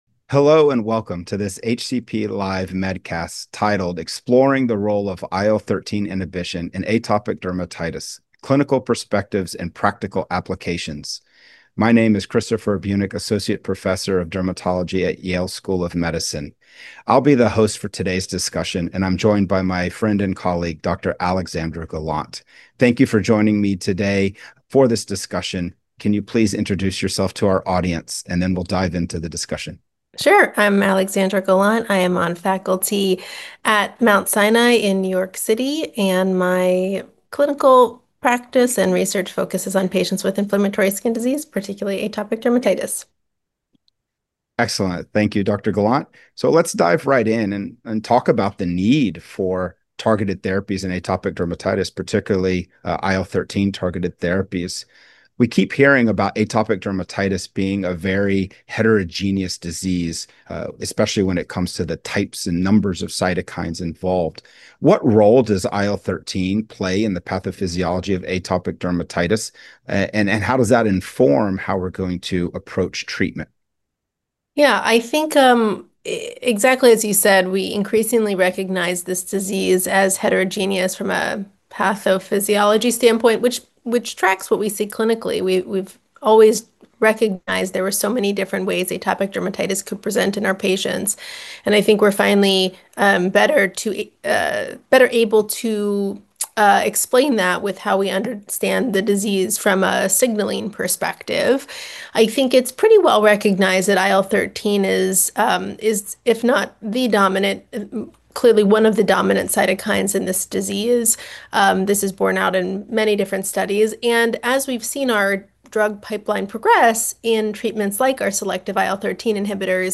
A pair of leading subject matter experts discuss the current landscape of atopic dermatitis, including unmet needs, and the role of IL-13 inhibition in the management.